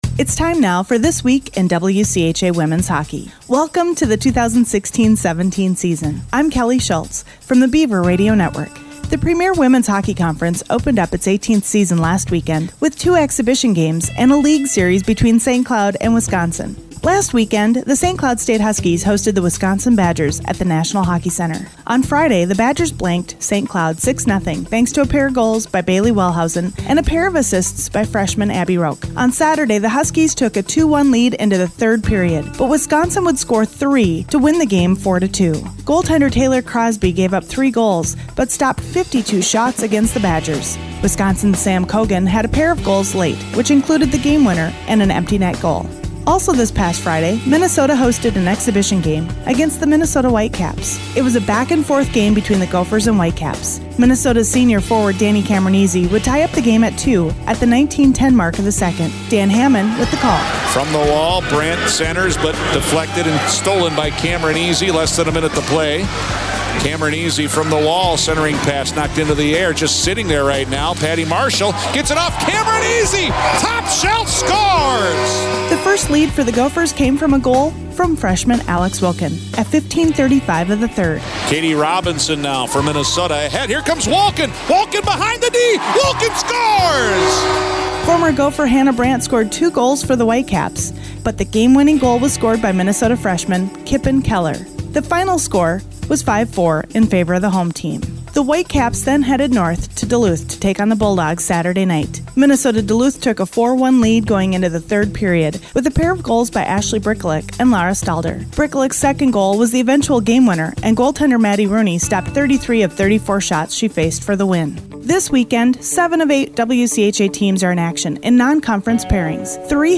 “This Week in WCHA Women’s Hockey” will feature audio highlights from coaches, players and play-by-play announcers from around the league.